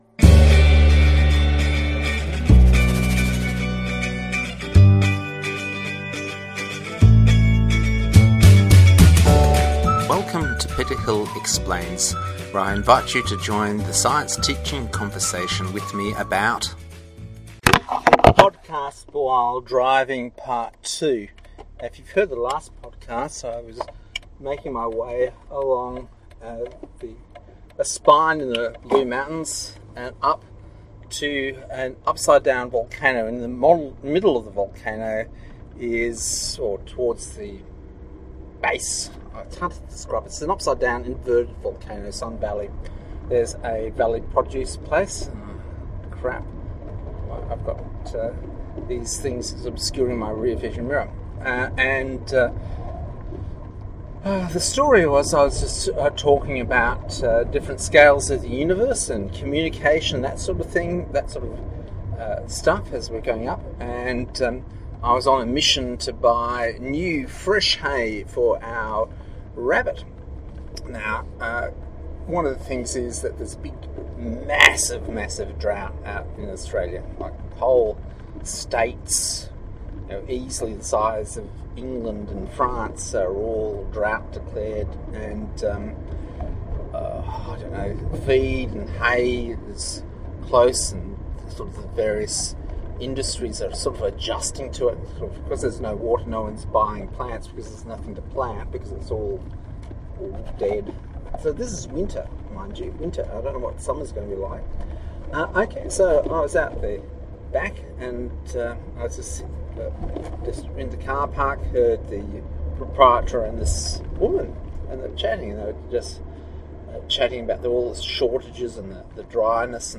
So a nice chat and we will be back.